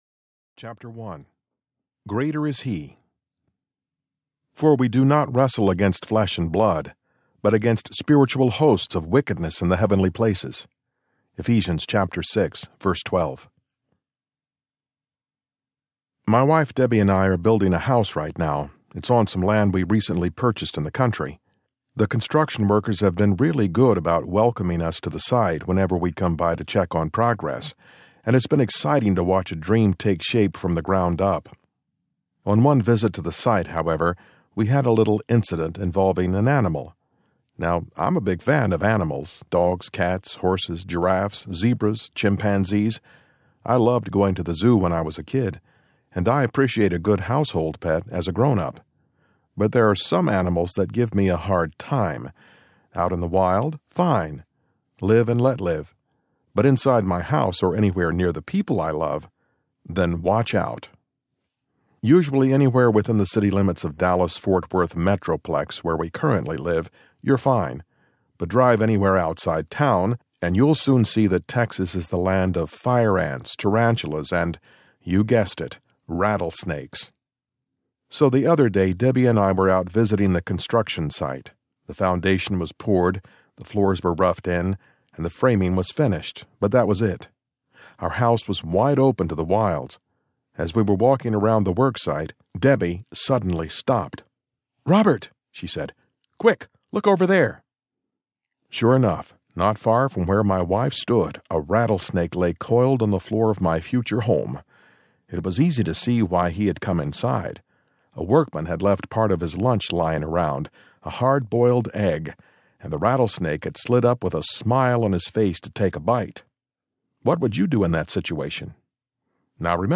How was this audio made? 6.7 Hrs. – Unabridged